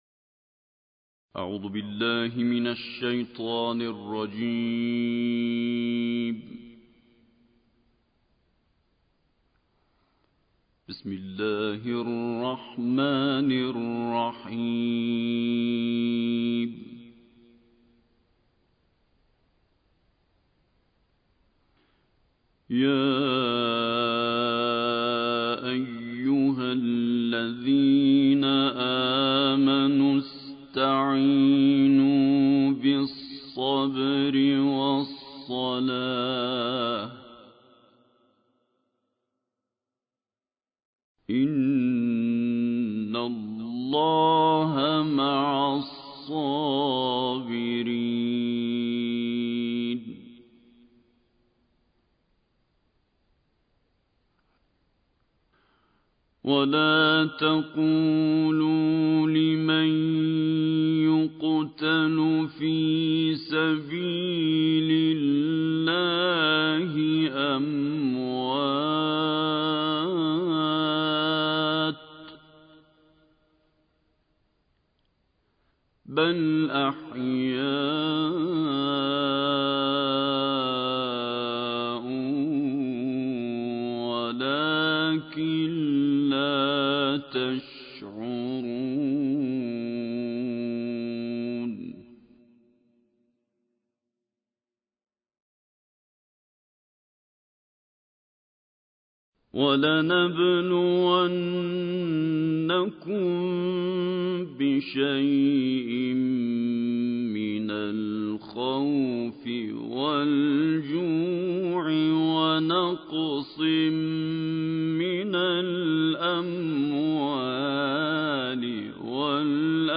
دانلود قرائت سوره بقره آیات 153 تا 167 - استاد سعید طوسی